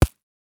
Body armor 3.wav